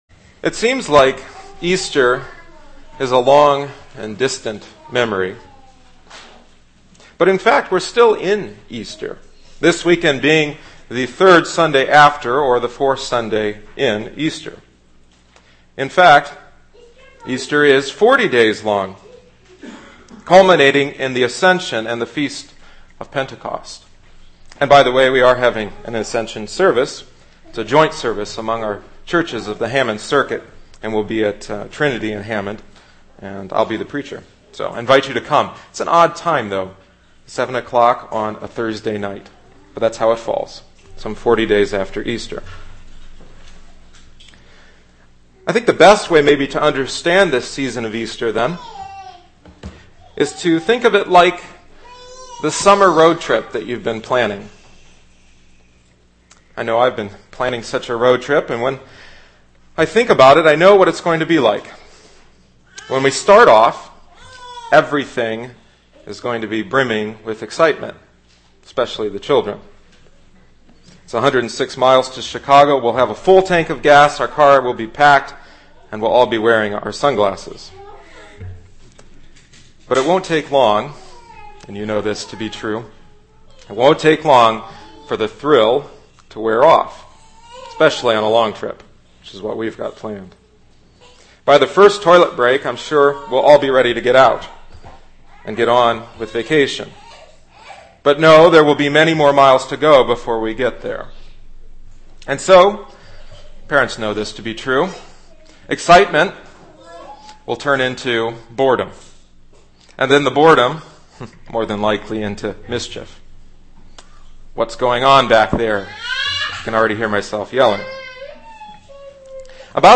This sermon was preached from an outline.